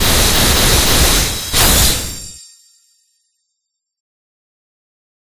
Particles3.ogg